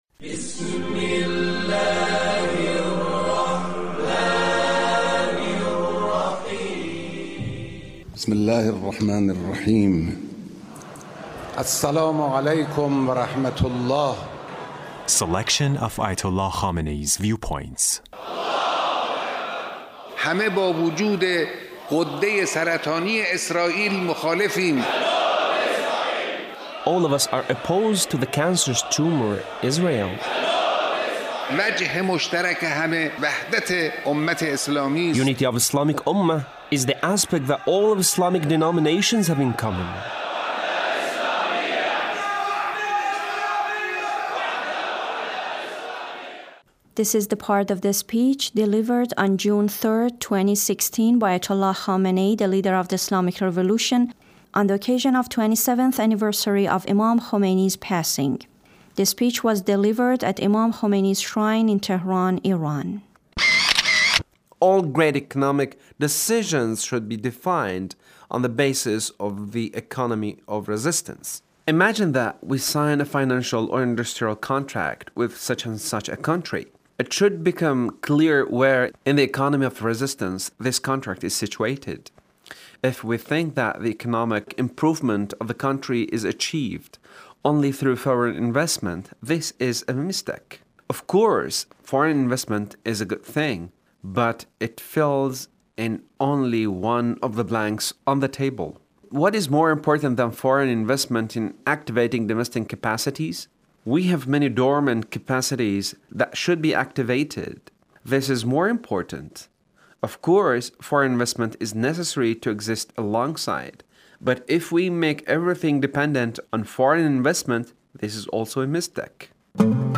Leader's speech (1347)
The Leader's speech Imam Khomeini (RA)